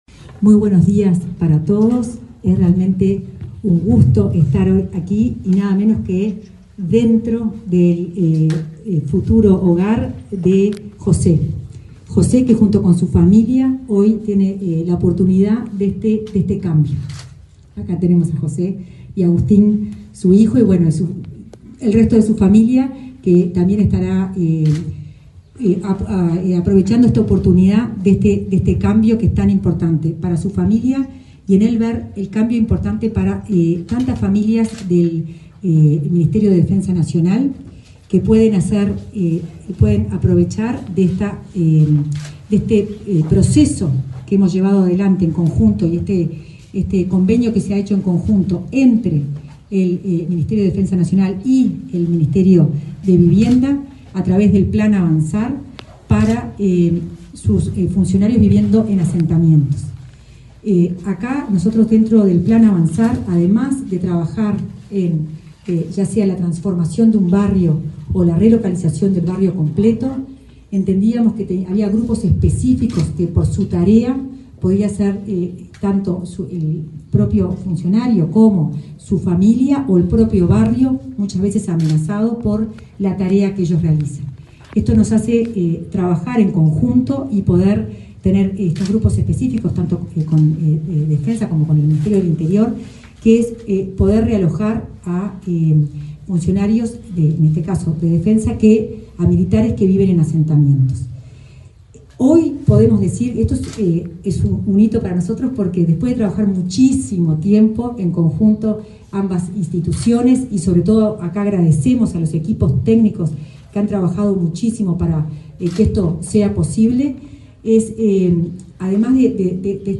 Palabras de autoridades en acto de entrega de vivienda
Palabras de autoridades en acto de entrega de vivienda 22/10/2024 Compartir Facebook X Copiar enlace WhatsApp LinkedIn La directora nacional de Integración Social y Urbana del Ministerio de Vivienda, Florencia Arbeleche; el subsecretario de Defensa Nacional, Marcelo Montaner, y el ministro de Vivienda, Raúl Lozano, participaron en el acto de entrega de una vivienda a un efectivo de la marina que habita un asentamiento, en el barrio Cerro, de Montevideo.